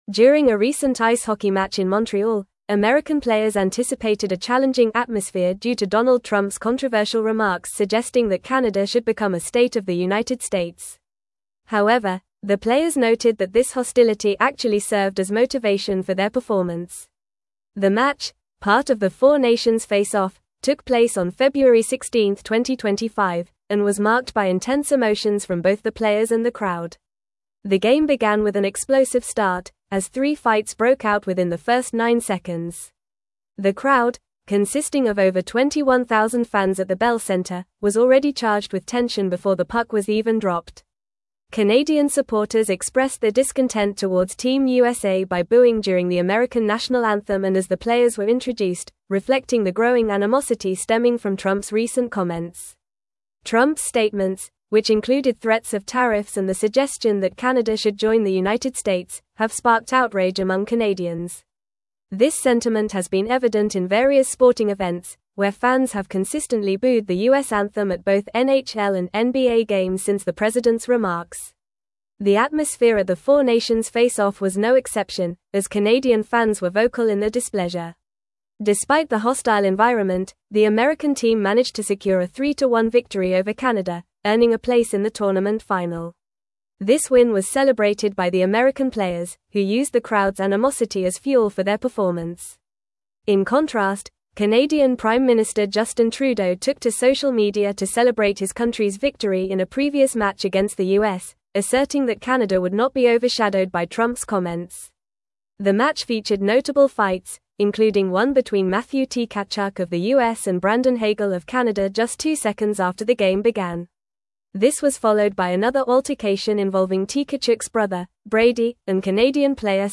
Fast
English-Newsroom-Advanced-FAST-Reading-Intense-Rivalry-Ignites-During-4-Nations-Face-Off.mp3